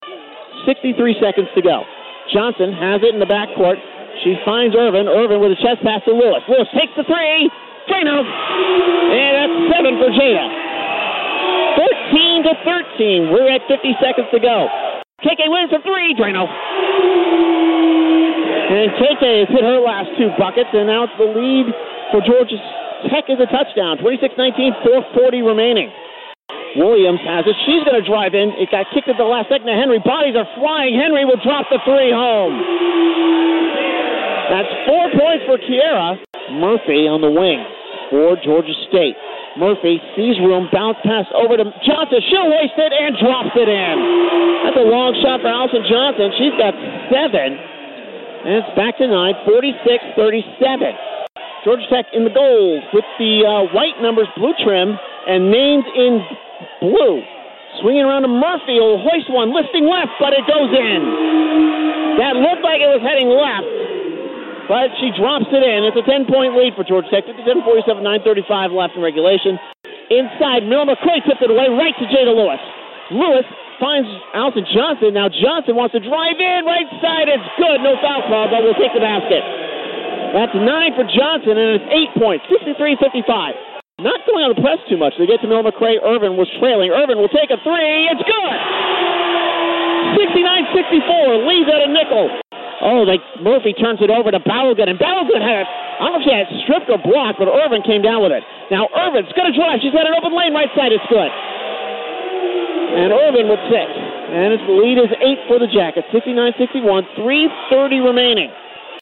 A collection of my play-by-play clips.
georgia-tech-georgia-state-audio-highlights.mp3